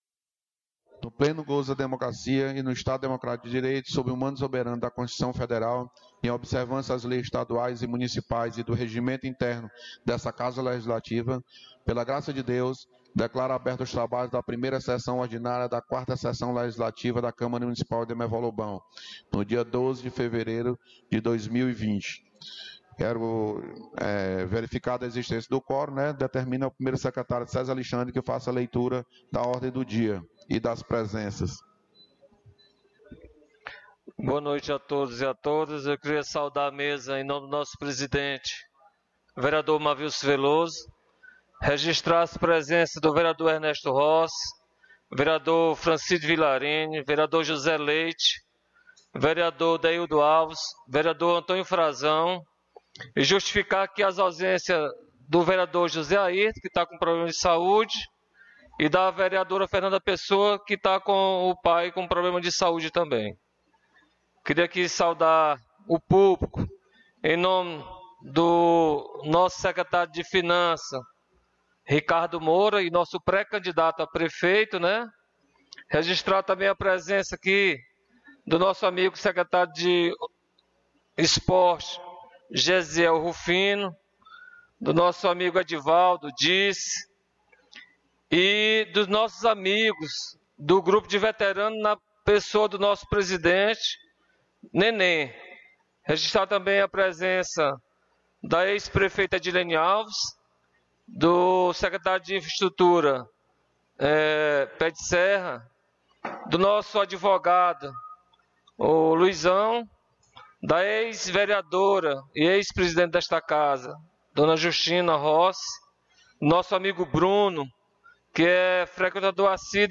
1ª Sessão Ordinária 12 de Fevereiro